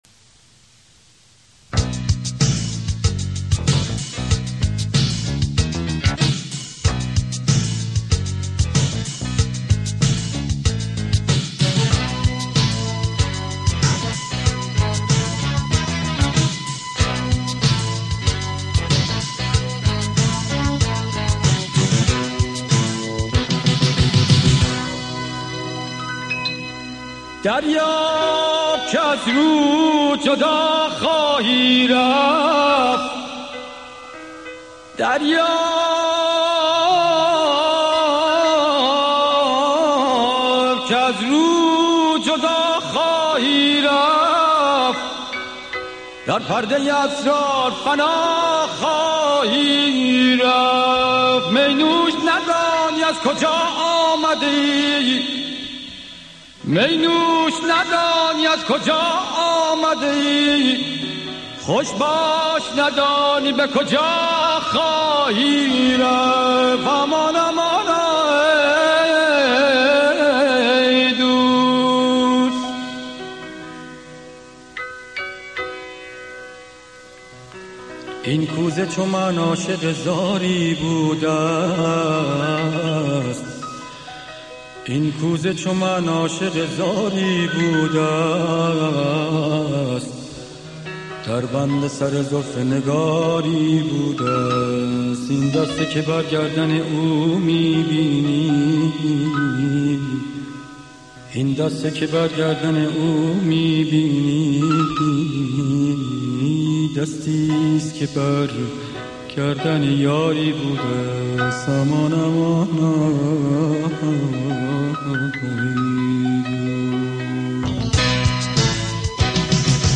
گیتار ۱۲ سیمی
آهنگسازی متأثر از موسیقی راک غربی
موسیقی پاپ ایران